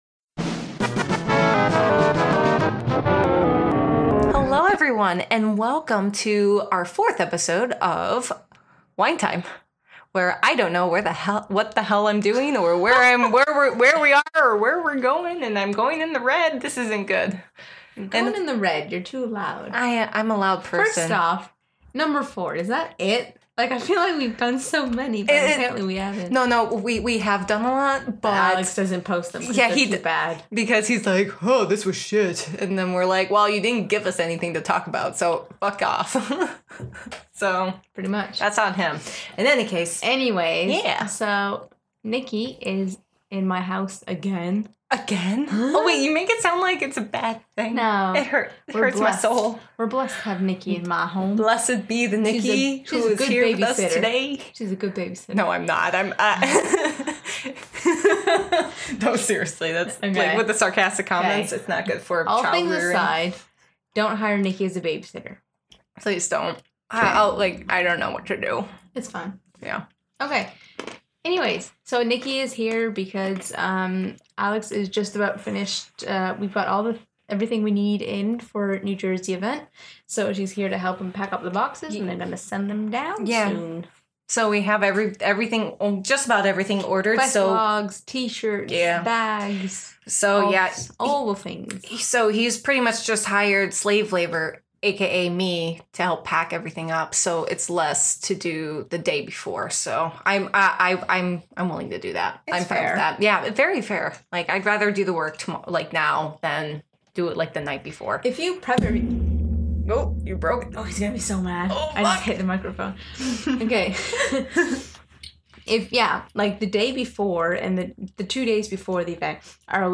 This episode contains strong language and poor articulation.